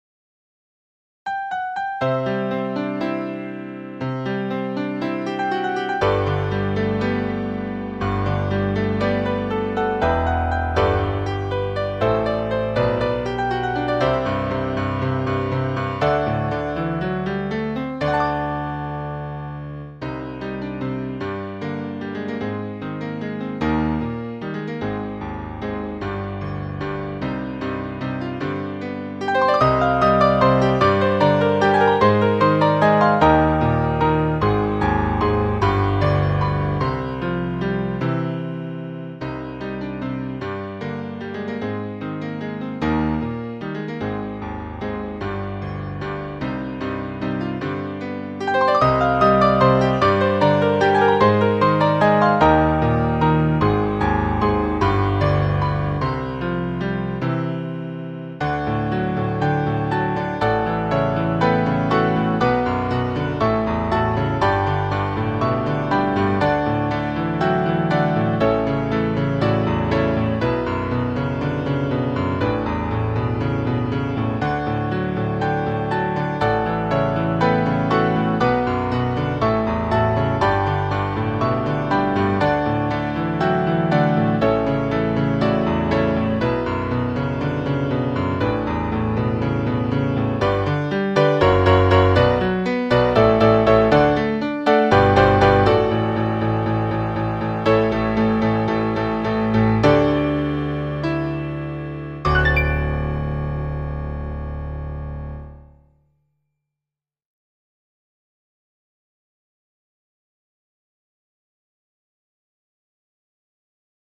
クラシック風味です。